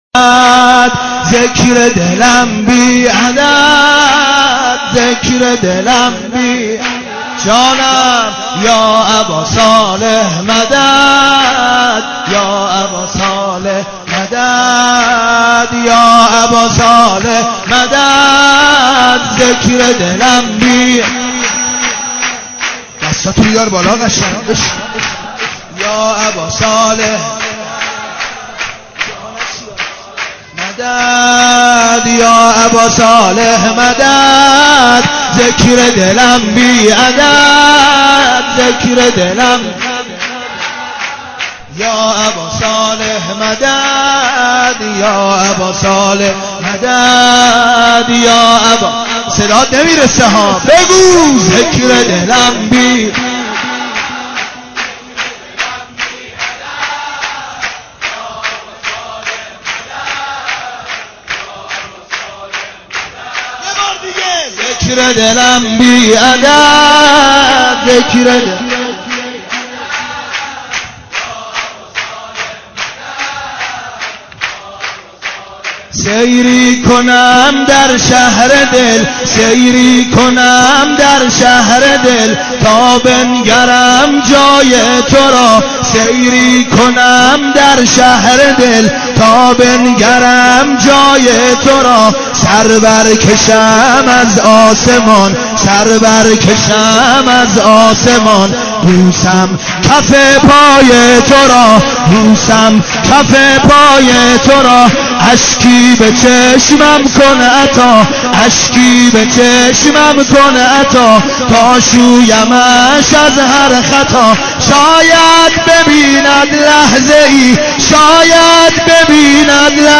دانلود مولودی امام زمان(عج